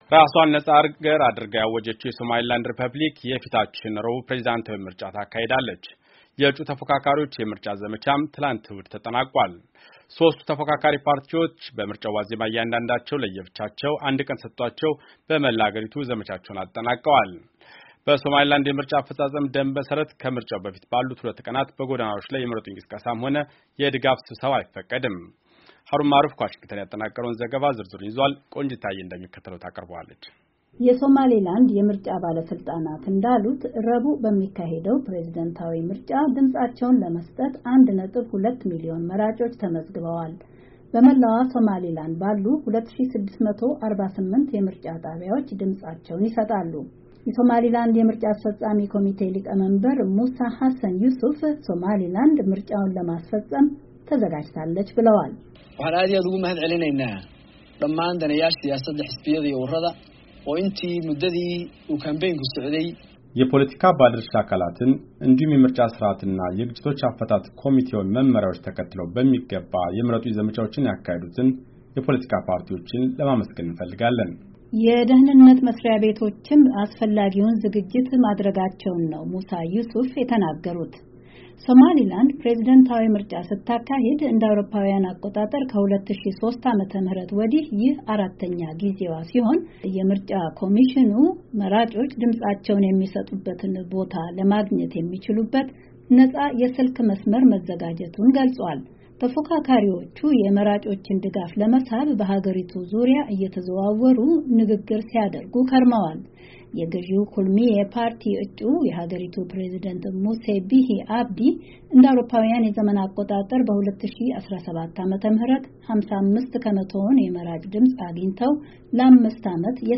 ዜና